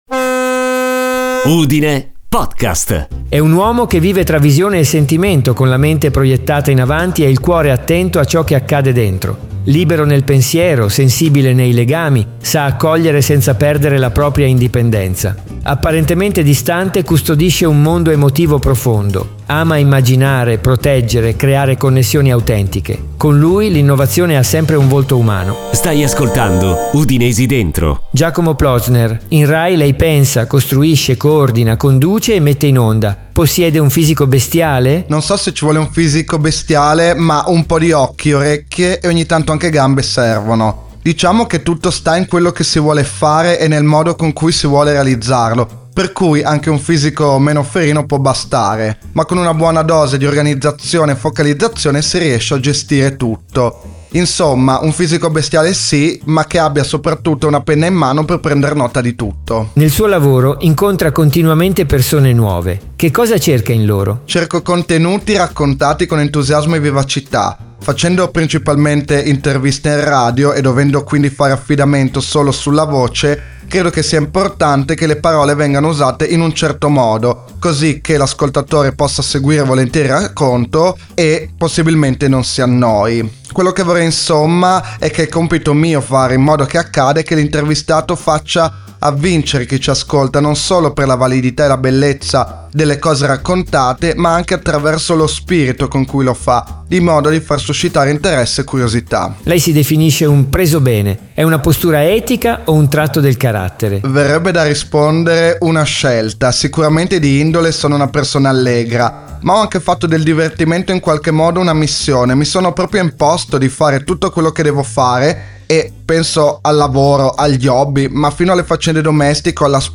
Nel suo lavoro incontra continuamente persone nuove. Che cosa cerca in loro?